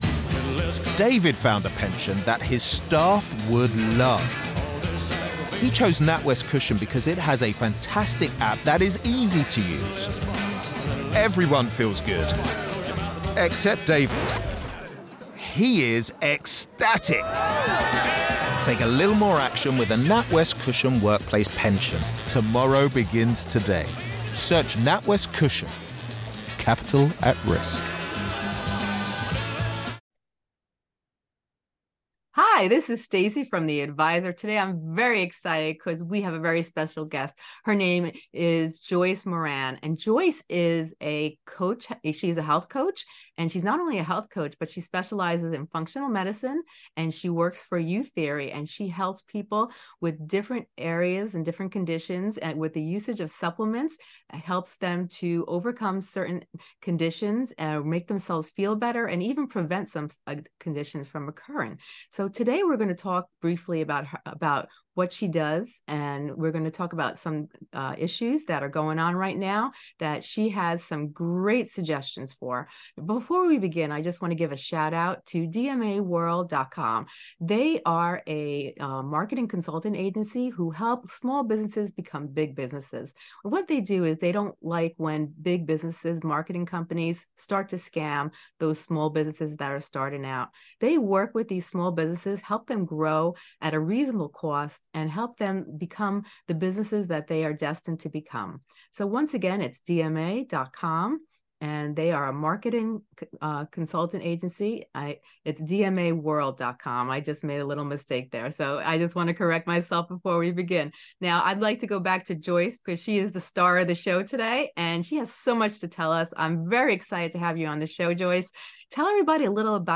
Tune in to this captivating conversation and learn how to navigate the holiday season with ease.